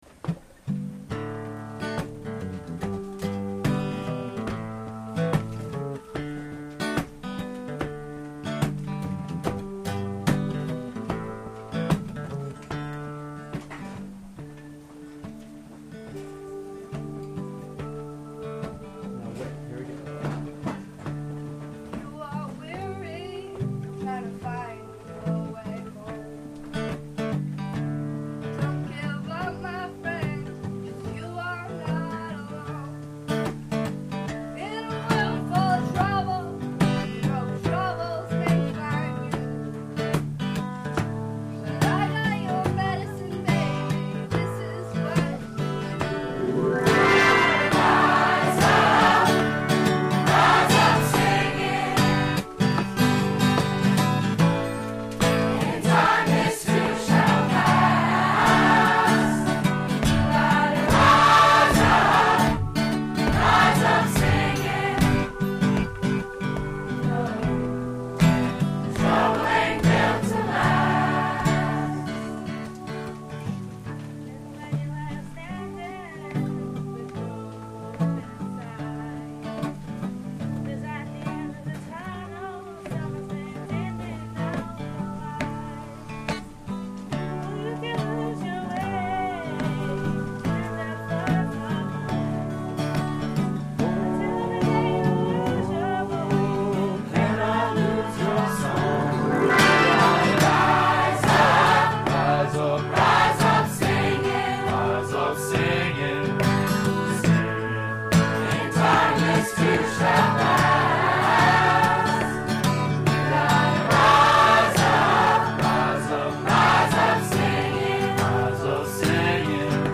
the students
Chamber, Choral & Orchestral Music
Chorus